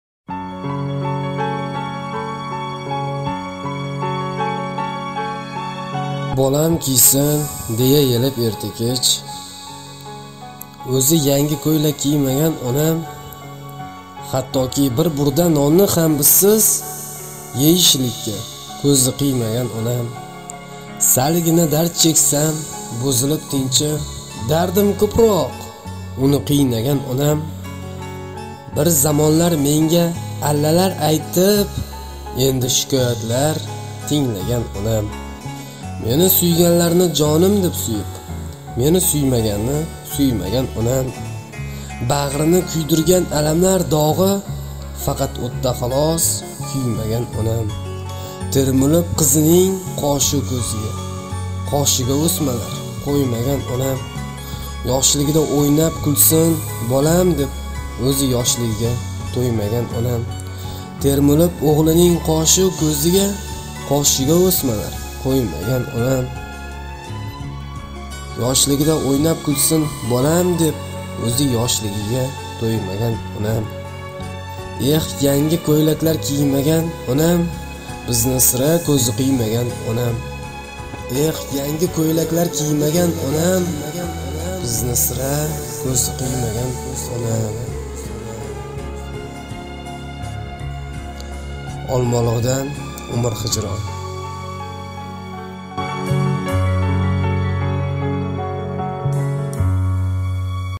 Umr_xijron__Ona_monolog_Rasmiy_kanalimiz_httpst_mejoinchatAAAAAFdu9gavq9dB5ysiqA.mp3